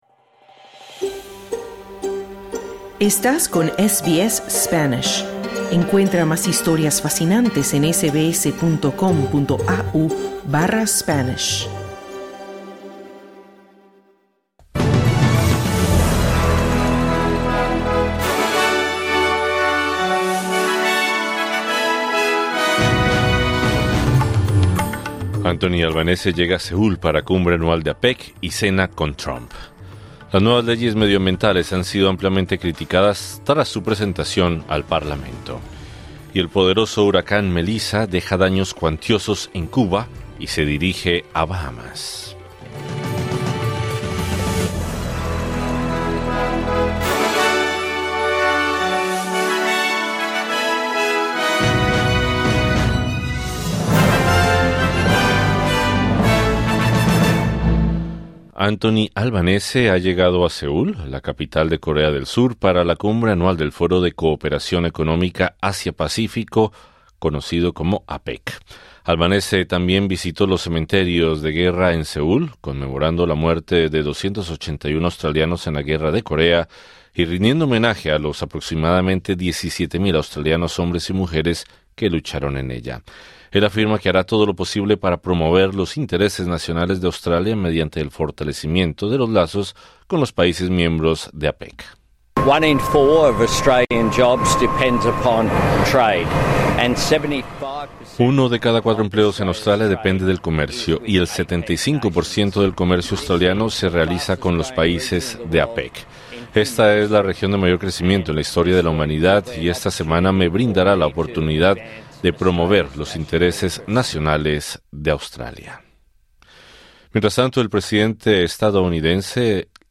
Anthony Albanese ha llegado a Seúl, la capital de Corea del Sur, para la cumbre anual de APEC. Donald Trump elogió al primer ministro australiano durante la cena de gala. Escucha el boletín de noticias de este jueves 30 de octubre 2025.